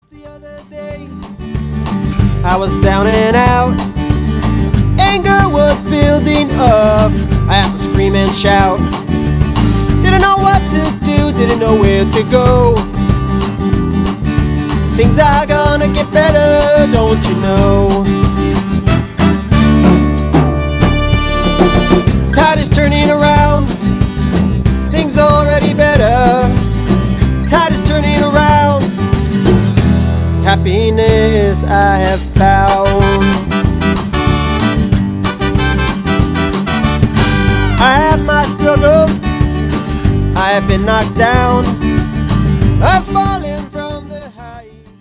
Contemporary Jewish music with a rock/folk vibe.